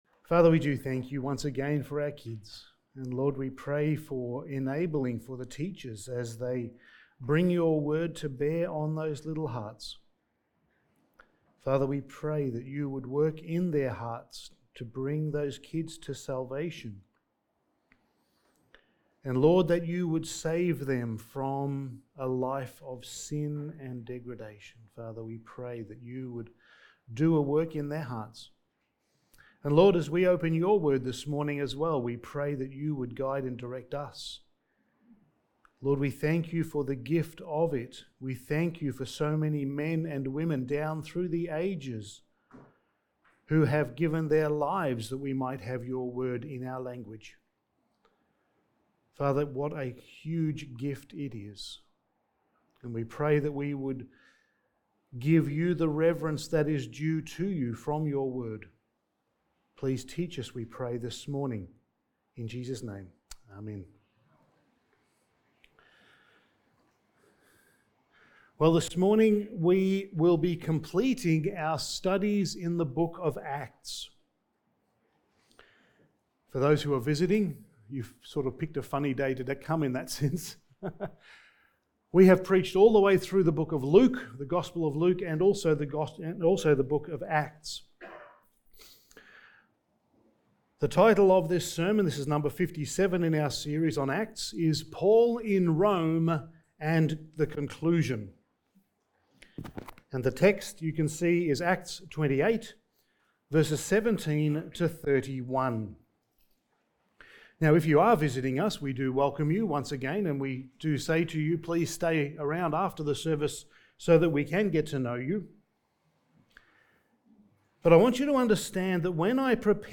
Passage: Acts 28:17-31 Service Type: Sunday Morning